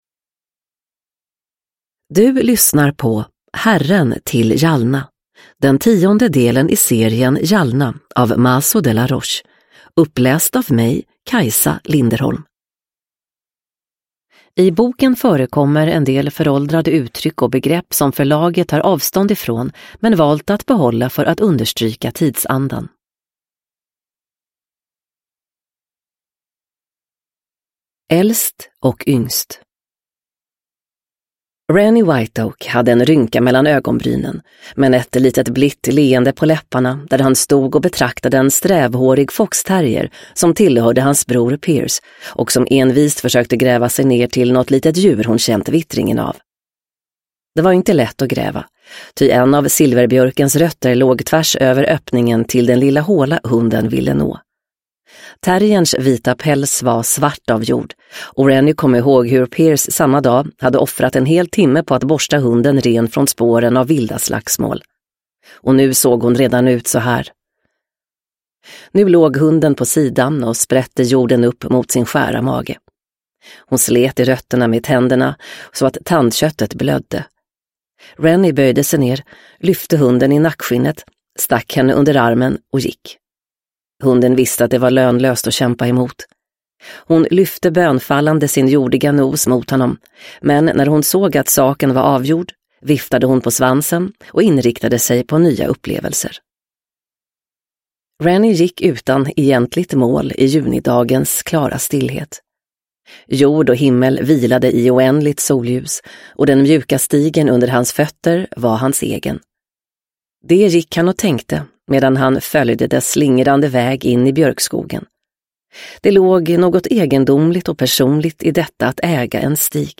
Herren till Jalna – Jalna 10 – Ljudbok – Laddas ner